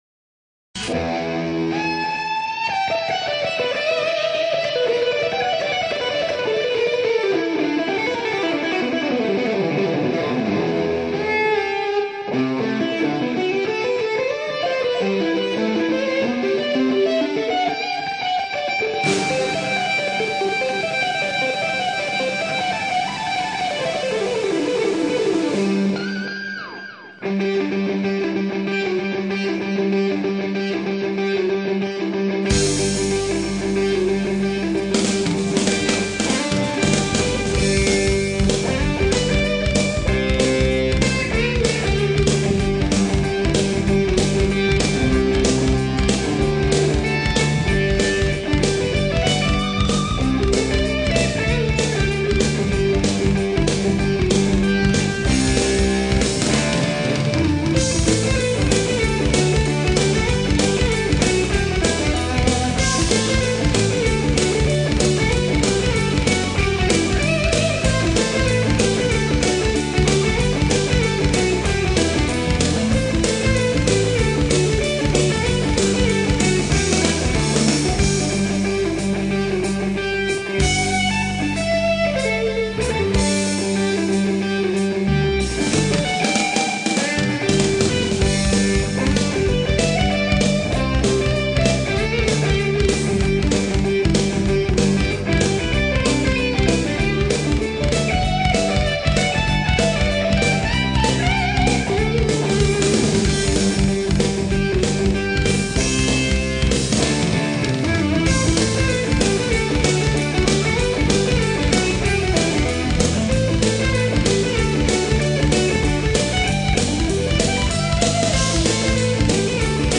0267-吉他名曲悬崖.mp3